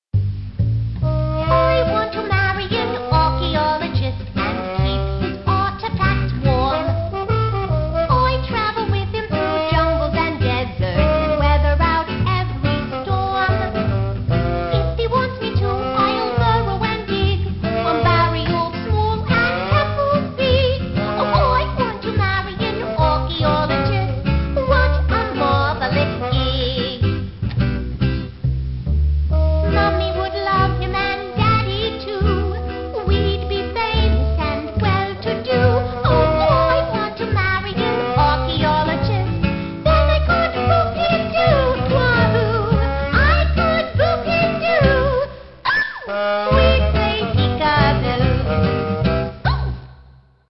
In-game digital version